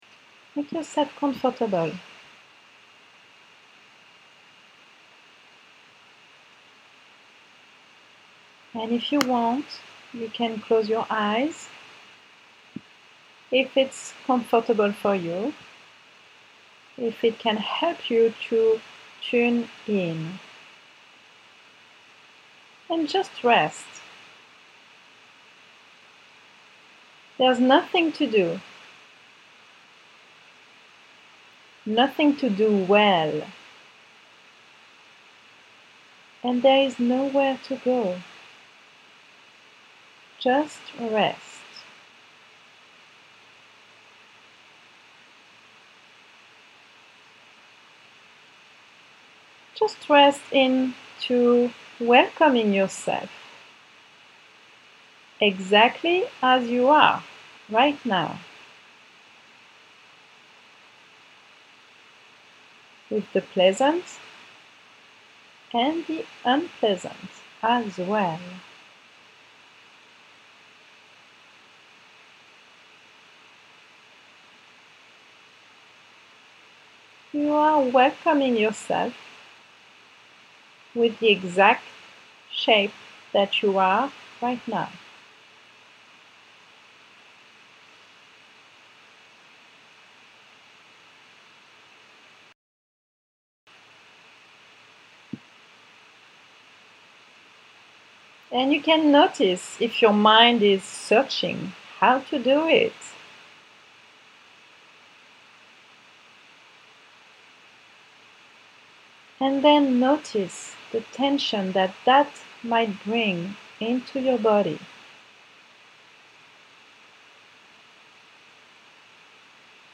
Resting into welcoming Meditation